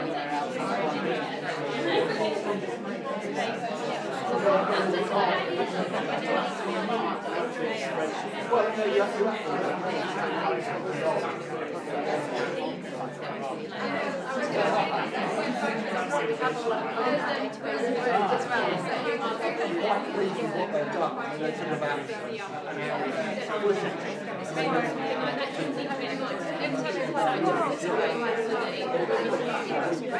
Lots and lots of chatter and buzz. Heritage Environment Record Officers, Conservation Officers, Community Archaeologists discuss social media, local engagement, heritage and lots more.